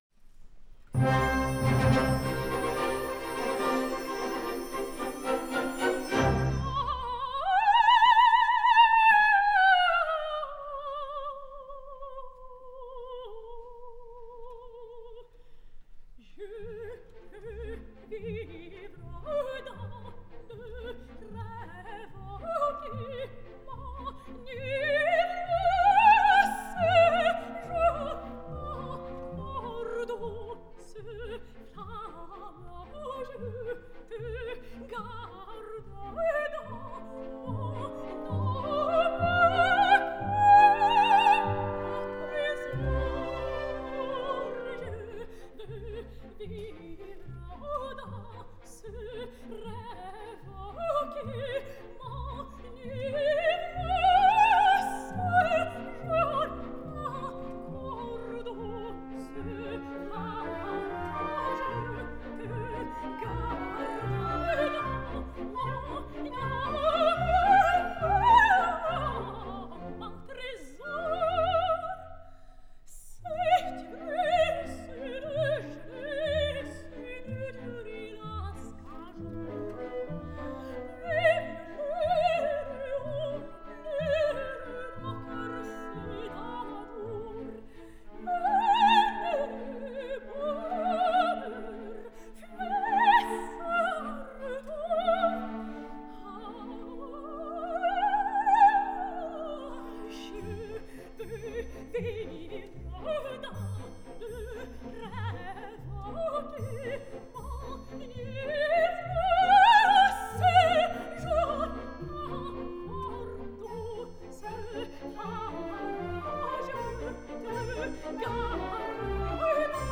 Opera Arias
Charles Gounod – Roméo et Juliette – Je veux vivre (La Monnaie / De Munt Symphony orchestra – conductor : Carlo Rizzi)
© Queen Elisabeth Competition 2011